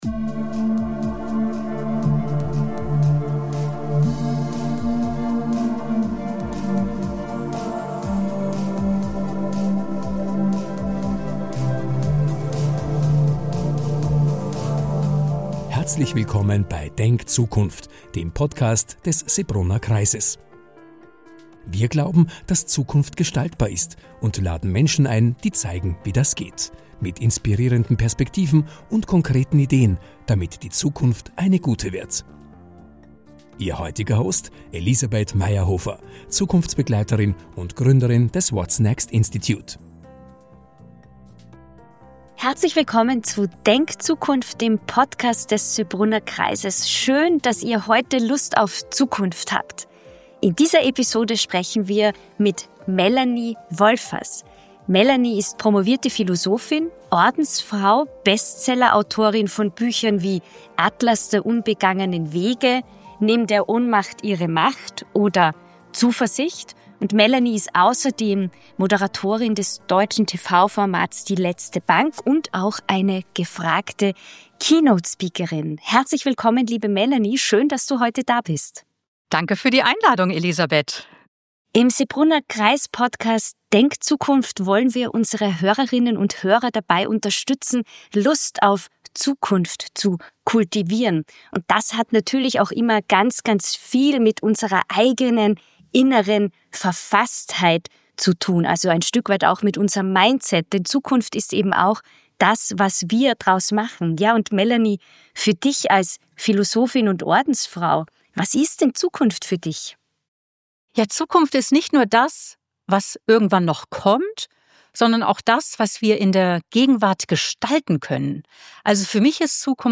Ein inspirierendes Gespräch über Werte, Verantwortung und die Kunst, neue Wege zu gehen.